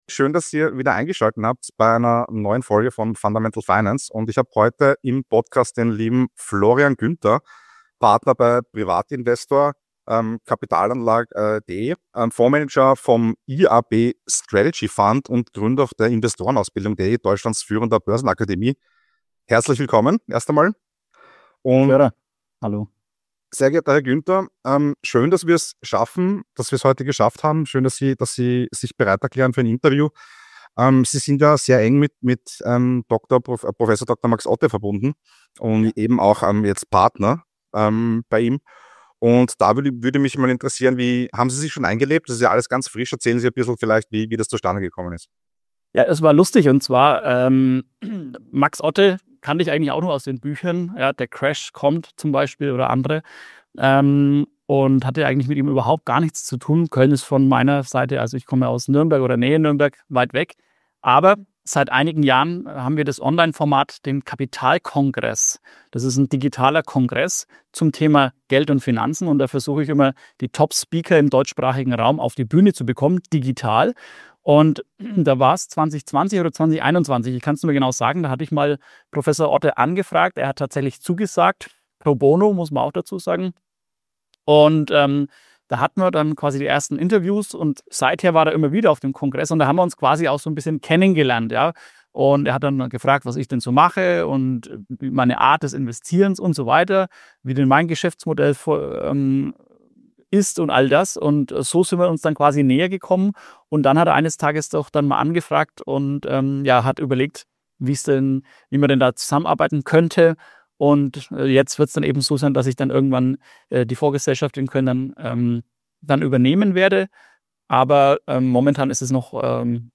analysiert im Gespräch mit Fundamental Finance messerscharf die aktuellen Herausforderungen von Deutschland bis zu den USA. Doch es gibt auch Lichtblicke: Entdecken Sie seine Einschätzungen zu Gold, Silber, Aktien und den Investment-Chancen für 2025.